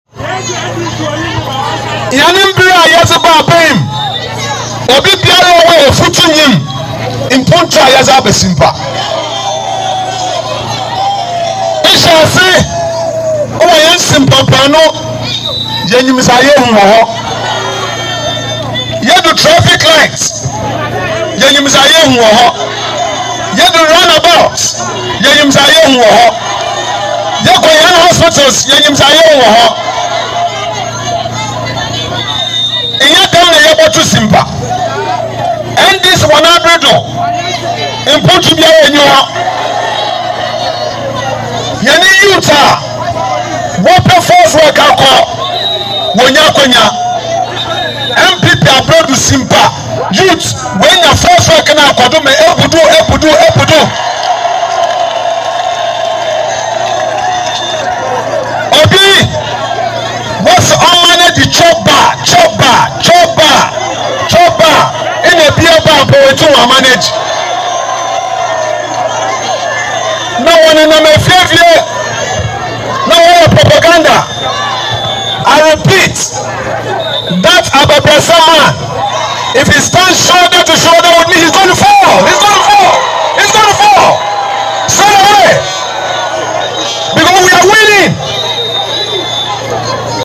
He said this at an “NPP All White Peace Walk” held on December 8 at Effutu, where he urged the crowd to ignore the NDC’s lies and vote for him in 2024.